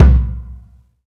TM88 HoodKick.wav